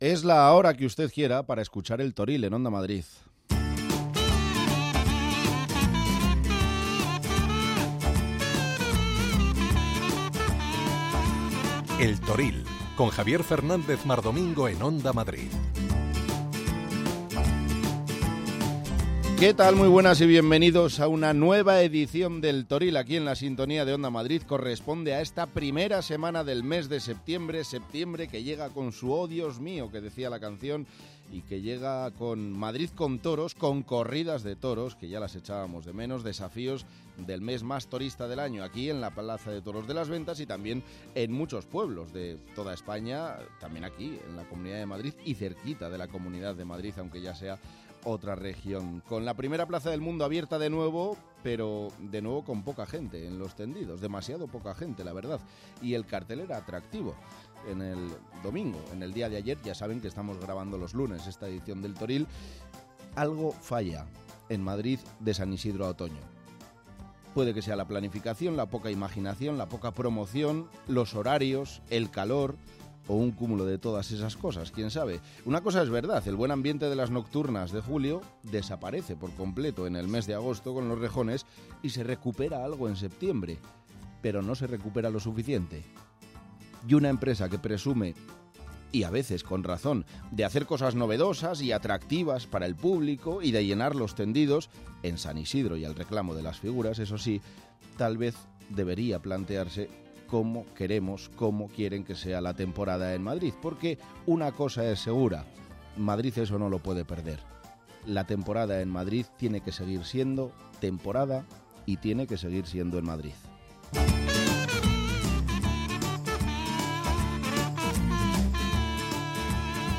Habrá información pura y dura y entrevistas con los principales protagonistas de la semana.